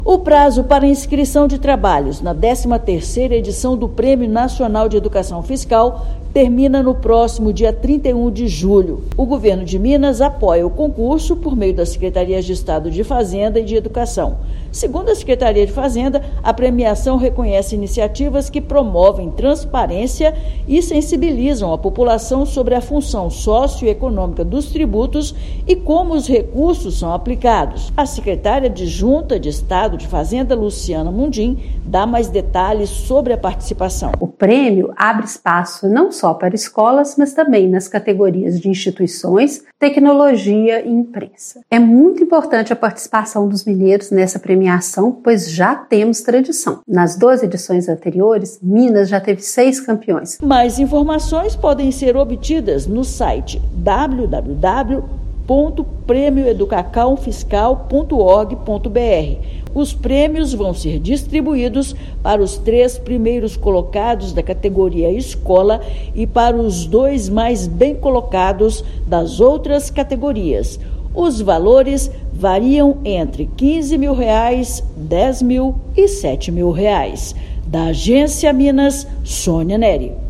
[RÁDIO] Secretaria de Fazenda alerta para o fim do prazo de inscrições do Prêmio Nacional de Educação Fiscal
Podem concorrer trabalhos nas categorias Escolas, Instituições, Tecnologia e Imprensa. Ouça matéria de rádio.